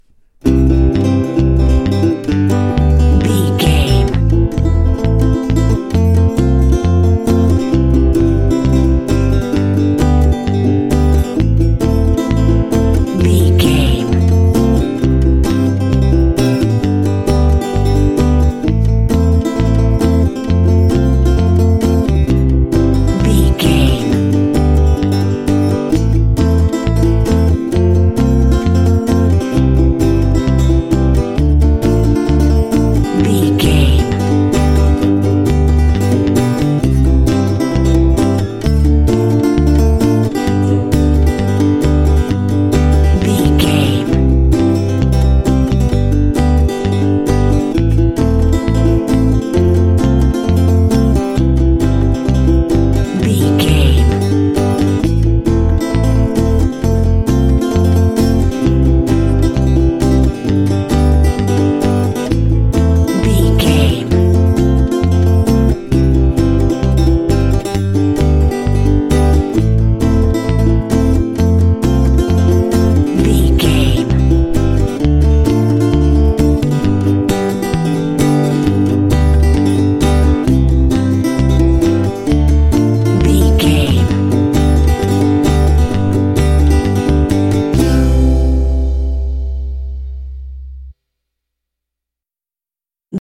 Ionian/Major
happy
banjo
bass guitar
playful